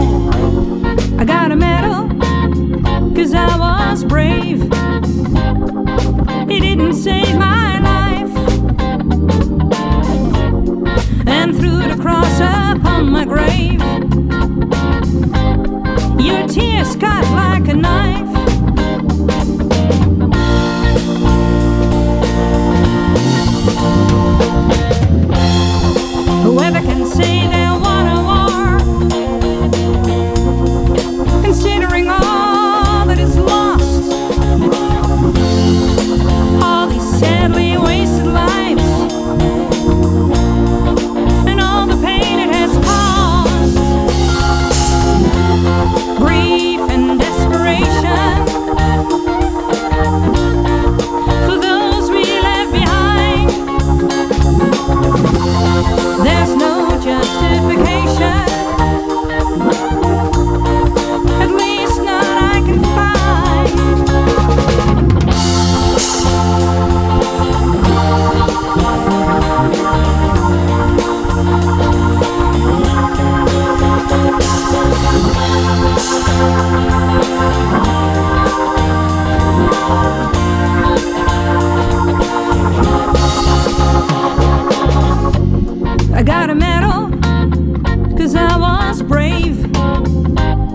Dutch jazz singer